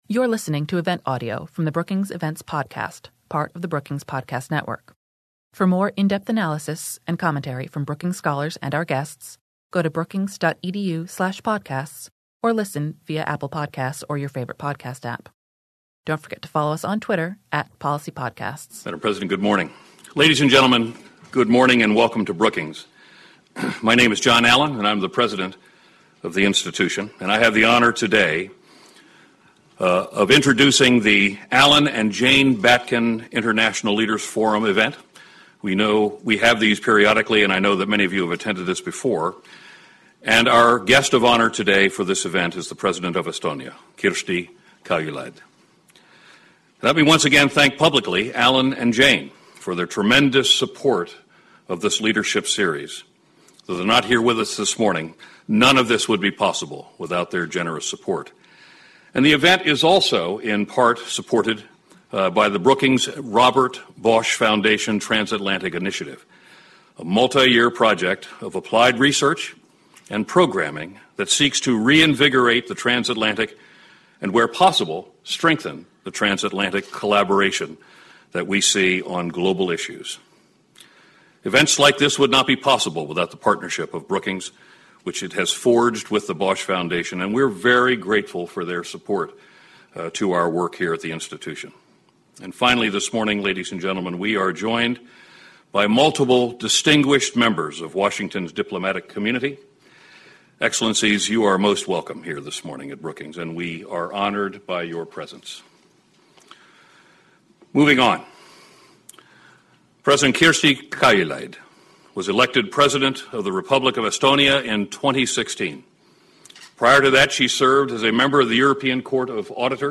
In a keynote address, President Kaljulaid discussed challenges facing Europe today and Estonia’s evolving role in the trans-Atlantic community. Following these remarks, Brookings President John R. Allen joined the President on stage for a conversation on these themes. Questions from the audience followed the discussion.